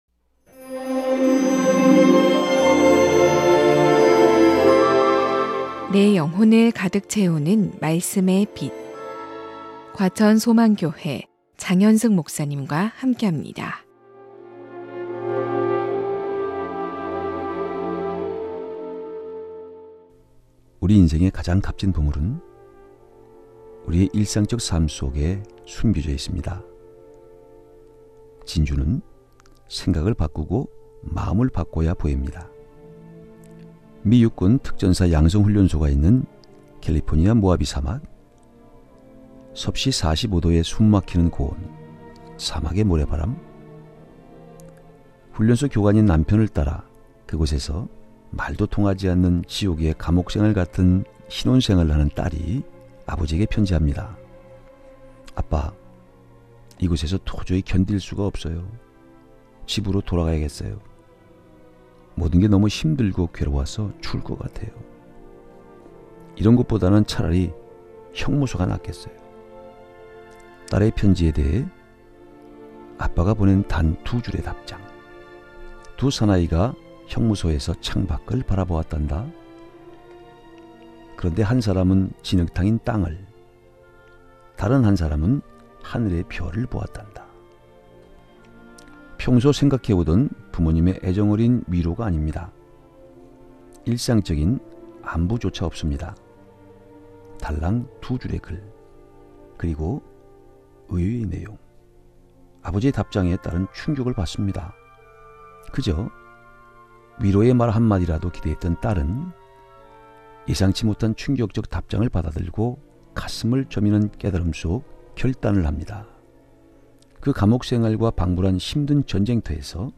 극동방송 설교 녹음파일 모아 듣기